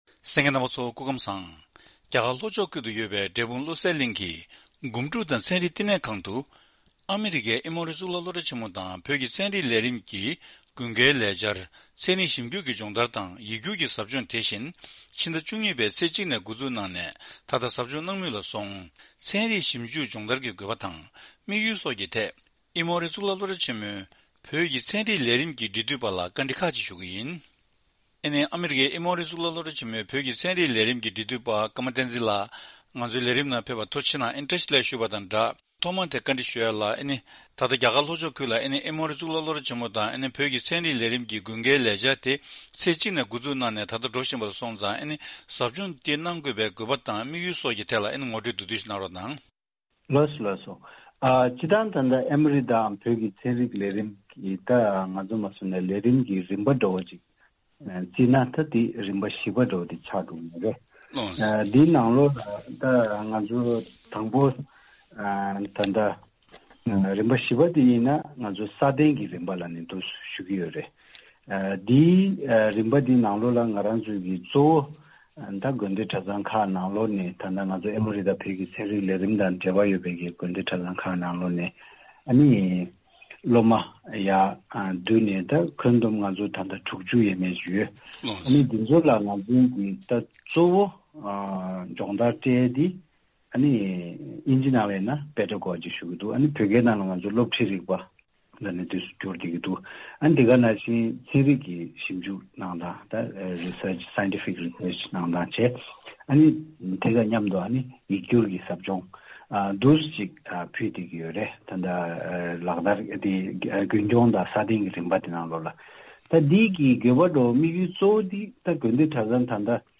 དེ་རིང་གི་བཅར་འདྲིའི་ལེ་ཚན་ནང་།